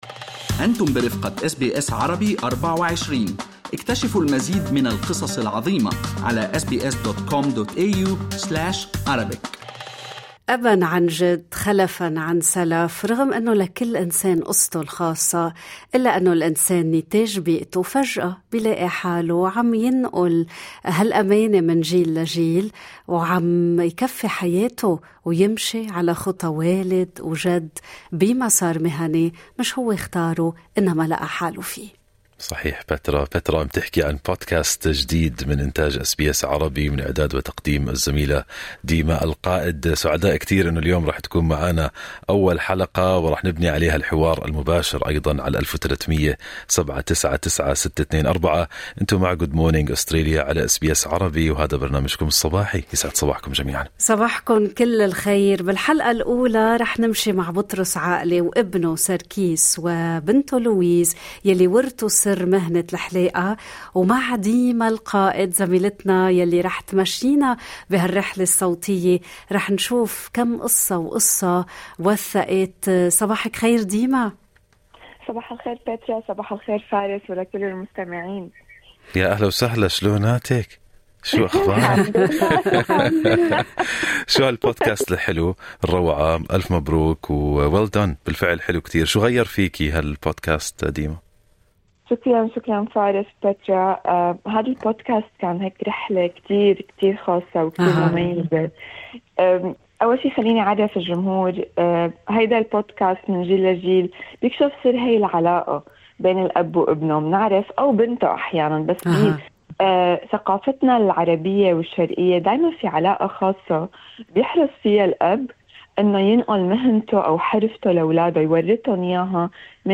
استطلع برنامج "صباح الخير استراليا" آراء الجالية العربية، عبر الأثير، عن وراثة المسار المهني بفعل الطبيعة ام التطبّع او التنشئة.